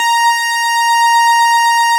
Added synth instrument
snes_synth_070.wav